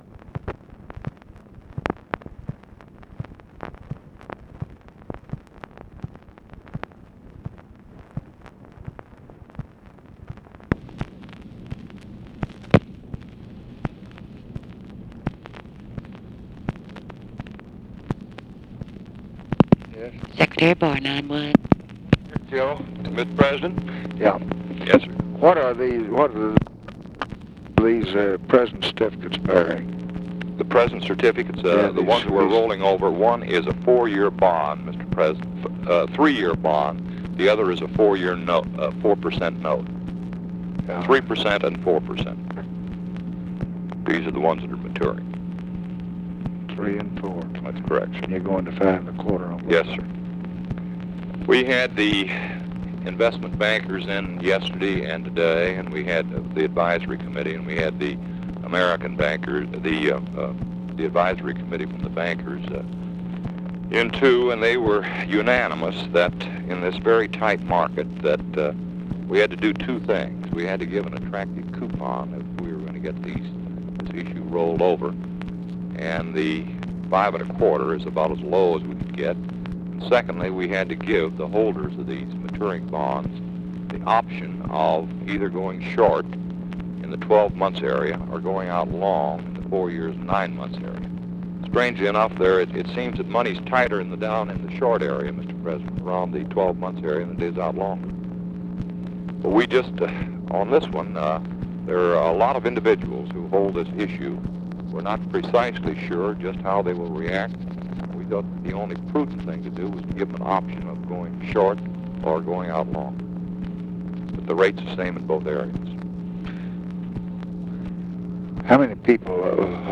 Conversation with JOSEPH W. BARR, July 27, 1966
Secret White House Tapes